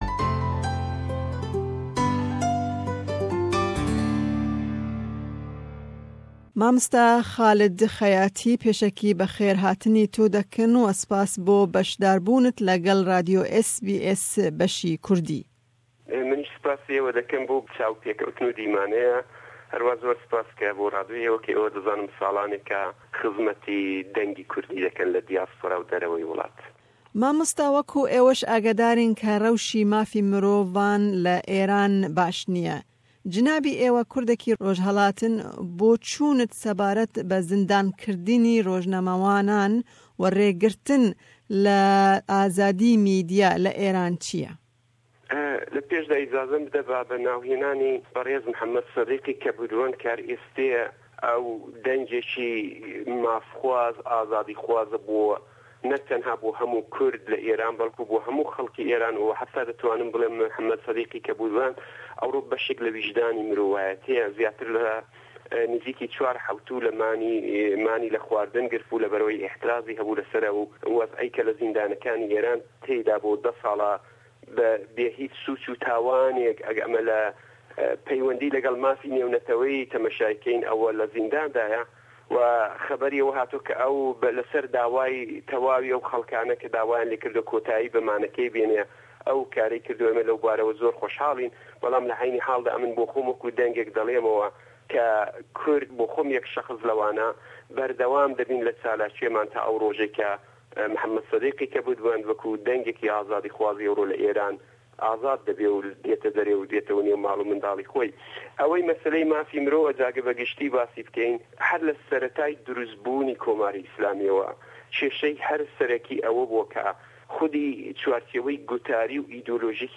Me hevpeyvînek sebaret bi binpêkirina mafêmirovan li Îranê û bi taybetî ya rojnamevanan pêk anî û pirsa Kurdî sebaret bi serxwebûna Kurdistanê di roja îroj de.